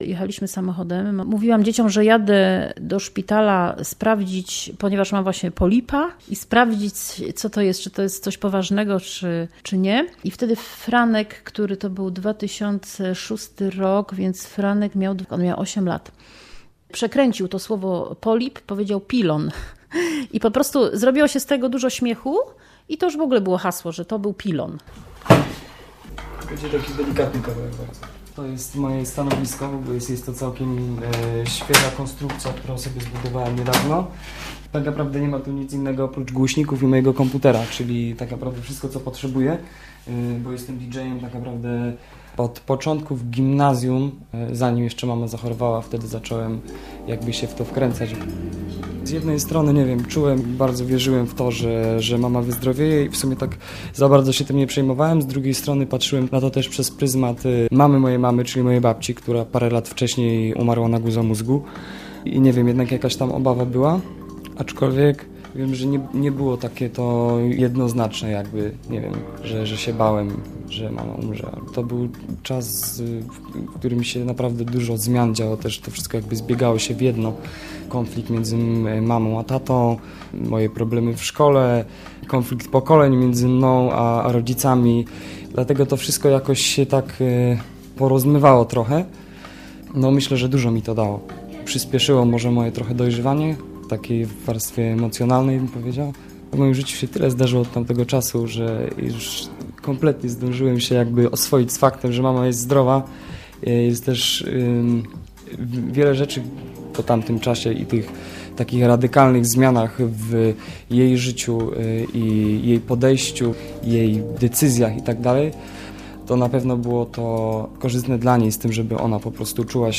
Ziarnko sosny - reportaż